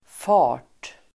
Uttal: [fa:r_t]